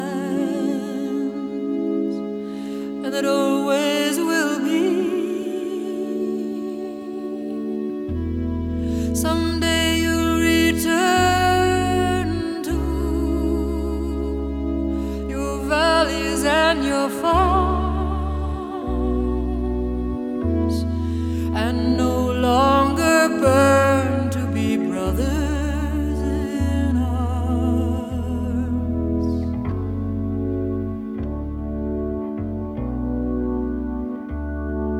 # Contemporary Folk